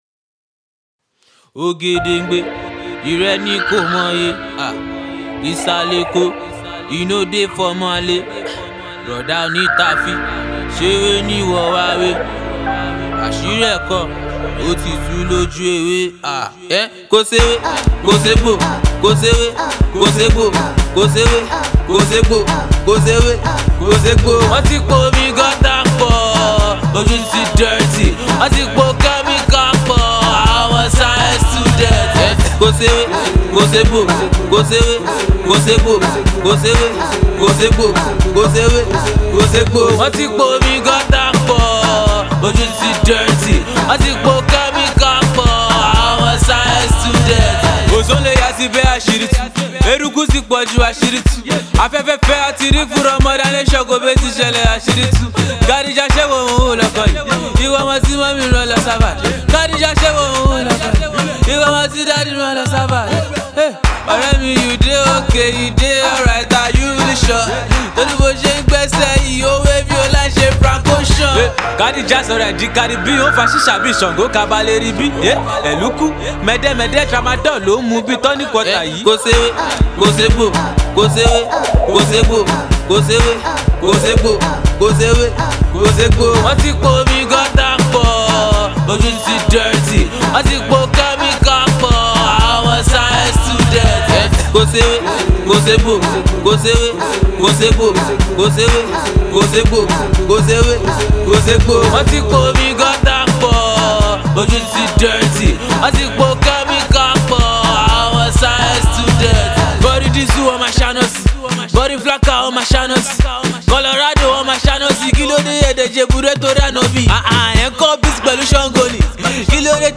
street lamba